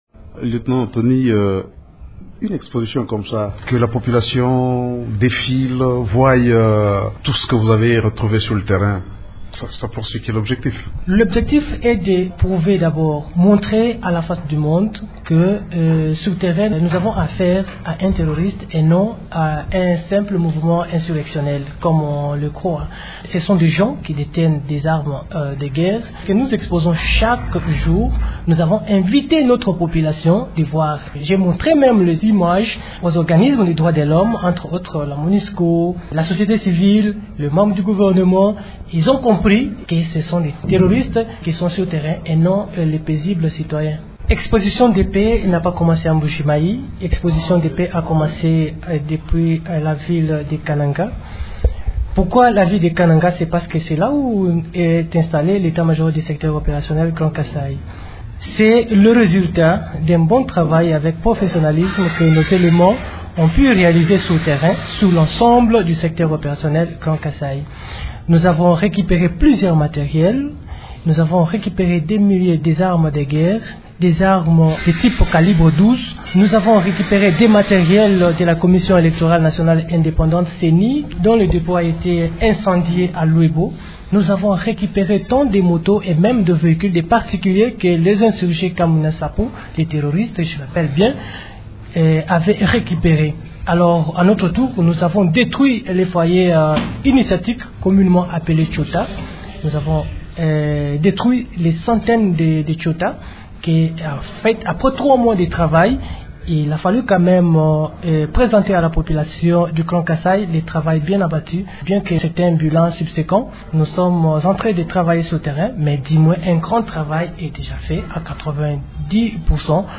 L'invité du jour, Émissions / retrait, M23, Ndeye Khady Lo, mandat, Monusco, résolution